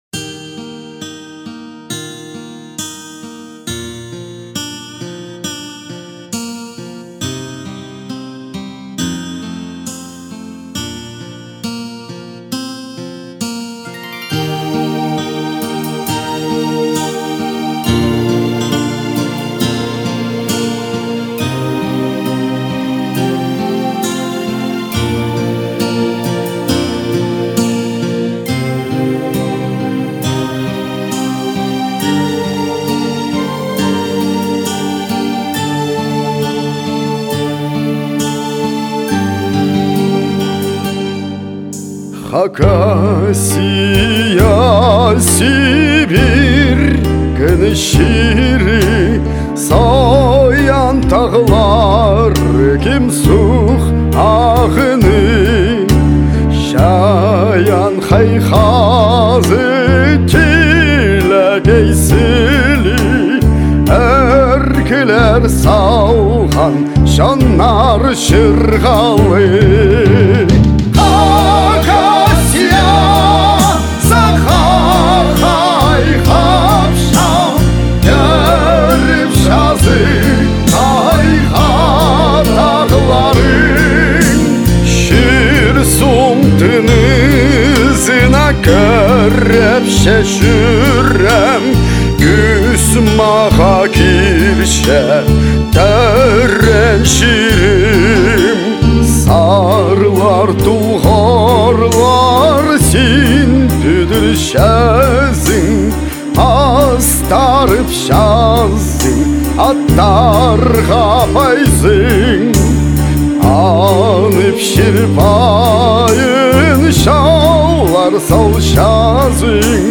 вокалды синтезаторлы запись